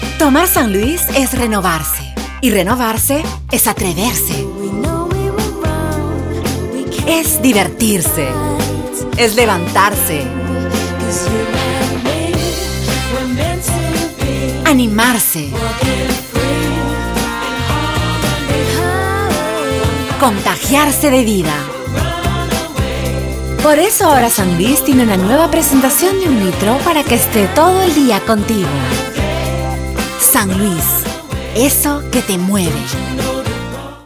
Espanhol - América Latina Neutro
Agua San Luis - Voz Mulher Jovem
Voz Jovem 00:36